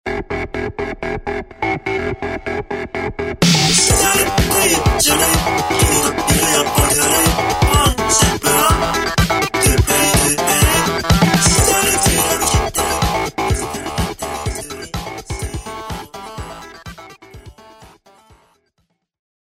結果２ループくらいの適当に作ってカイト使って歌わせたら結構面白く出来たので悔しい。
でも好きな声質出せたので面白かった。